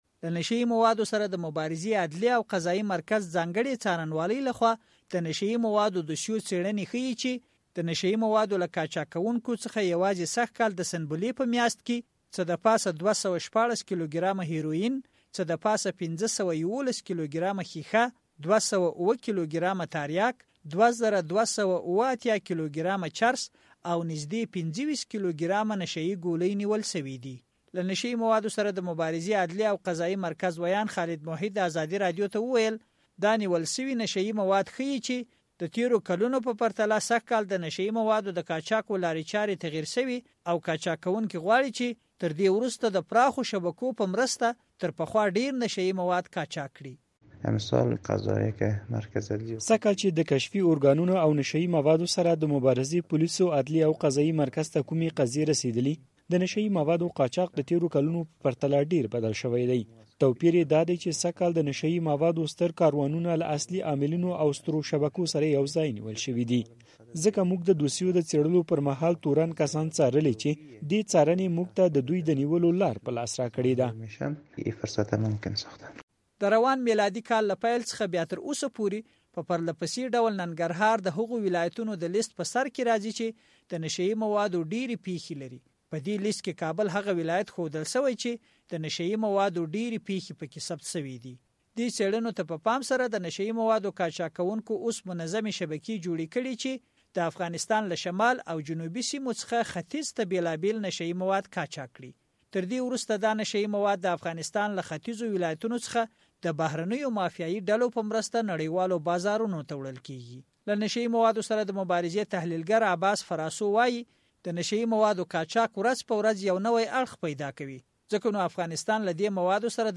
د نشه يي موادو د قاچاق کوونکو د پراخو شبکو په اړه راپور